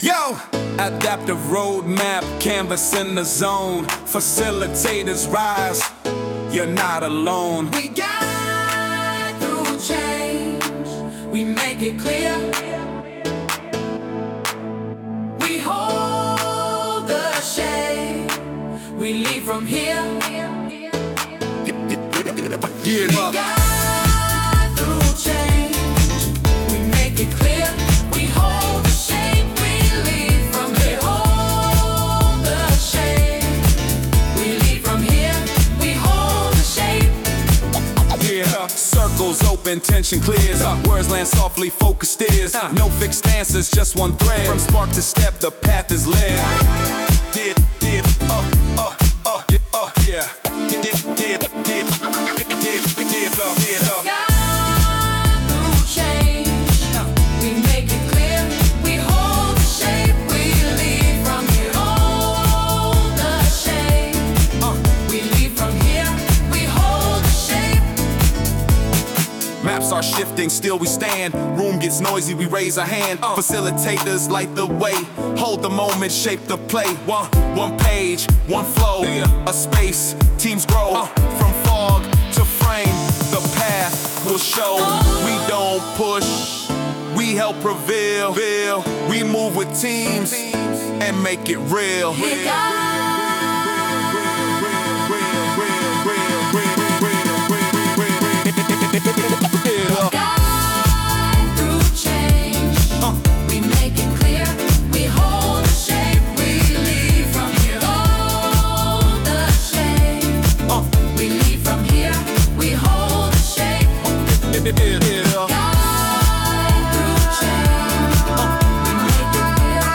In just 2:12 minutes, this soundtrack brings our facilitator spirit to life – bold, purposeful, and adaptive.